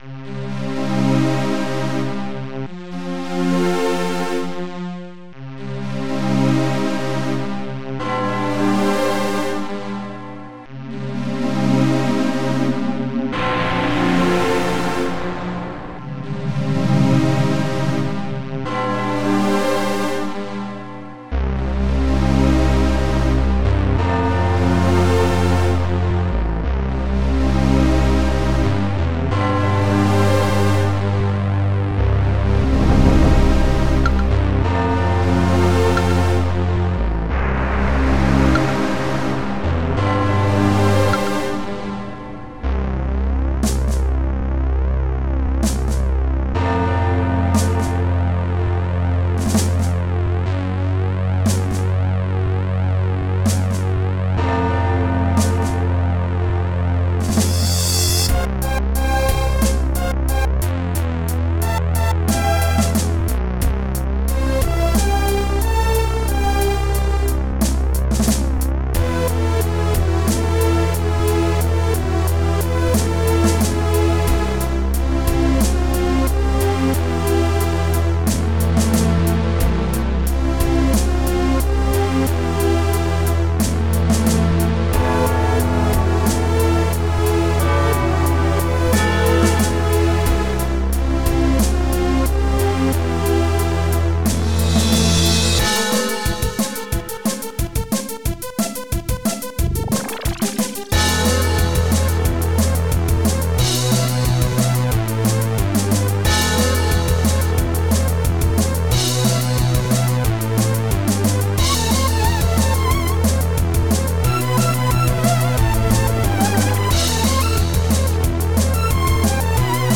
xm (FastTracker 2 v1.04)